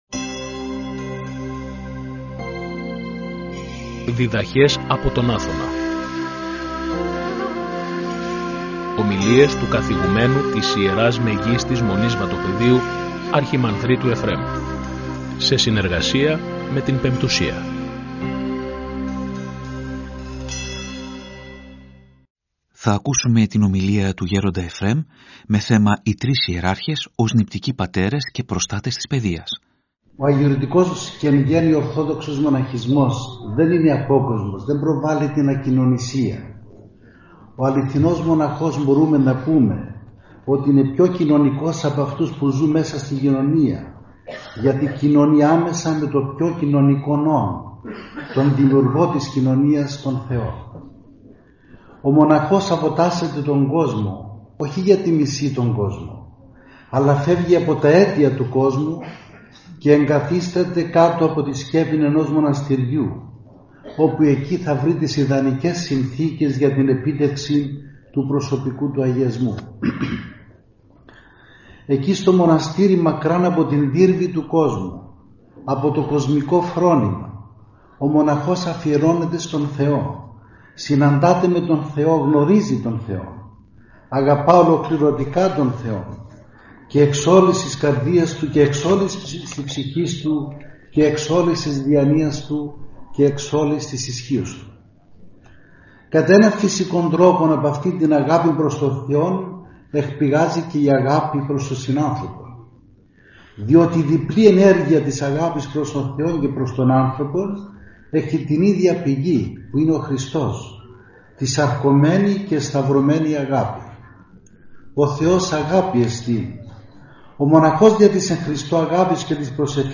Ομιλία
Η ομιλία μεταδόθηκε και από την εκπομπή «Διδαχές από τον Άθωνα» στη συχνότητα του Ραδιοφωνικού Σταθμού της Πειραϊκής Εκκλησίας την Κυριακή 26 Ιανουαρίου 2025.